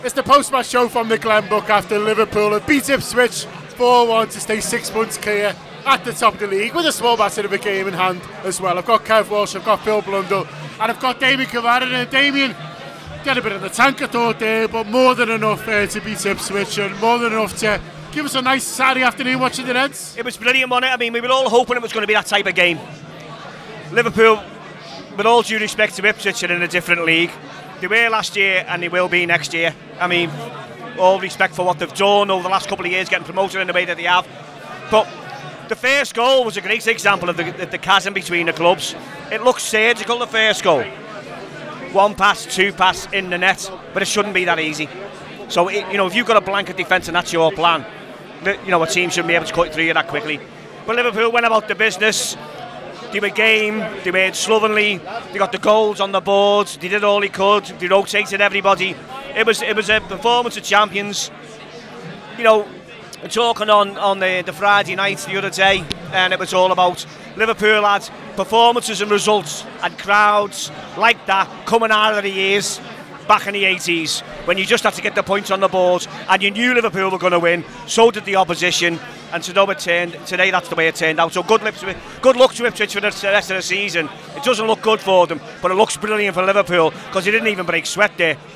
Below is a clip from the show – subscribe to The Anfield Wrap for more reaction to Liverpool 4 Ipswich Town 1…